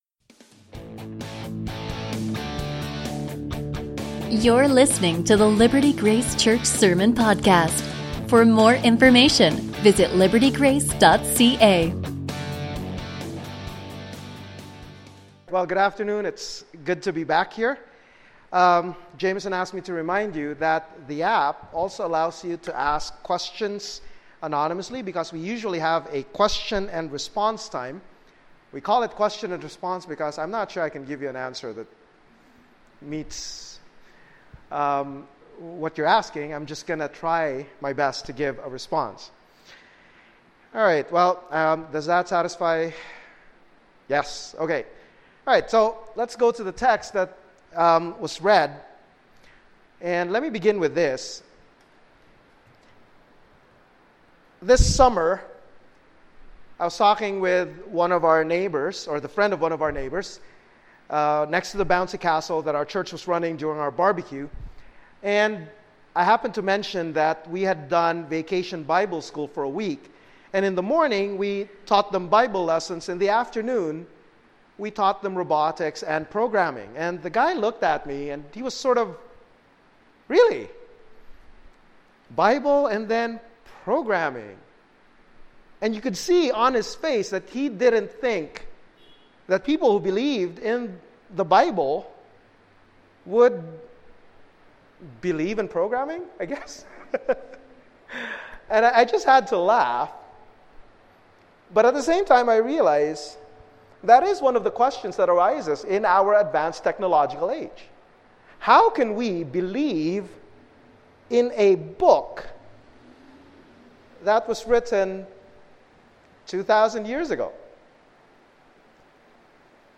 Sermons 2 Timothy Message